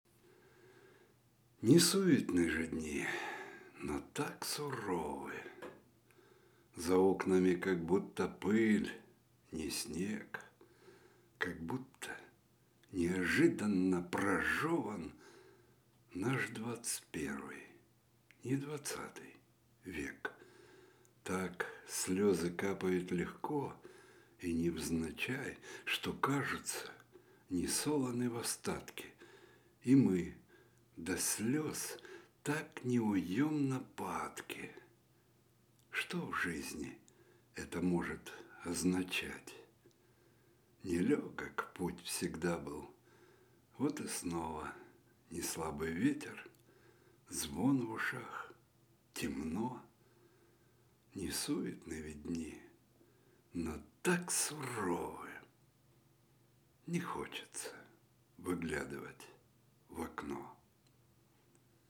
Аудиокнига Верификация. Стихи | Библиотека аудиокниг